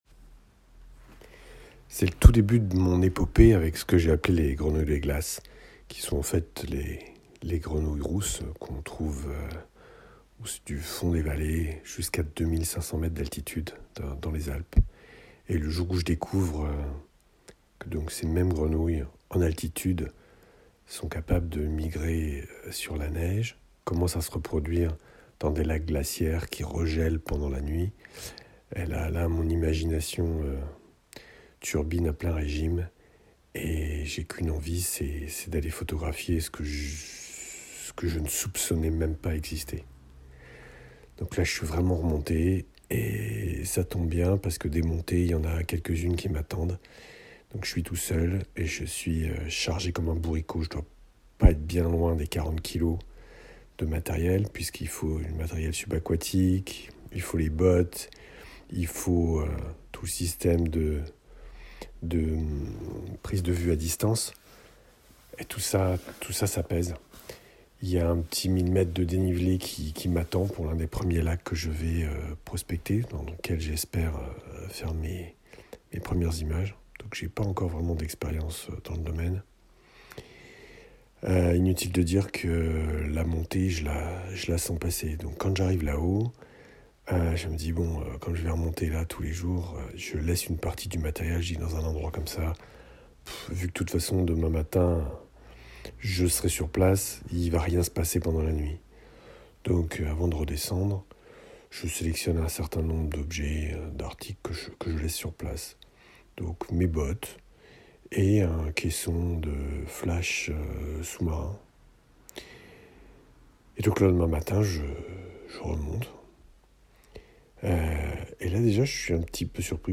marmottes-et-bottes-grenouilles-des-Alpesmp3.mp3